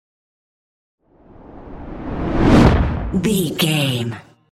Whoosh fire ball
Sound Effects
No
whoosh